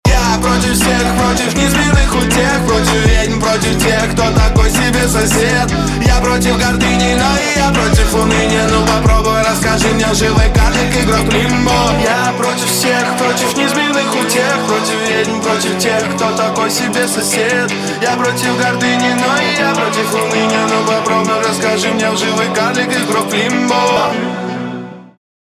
• Качество: 192, Stereo
громкие
русский рэп
злые
агрессивные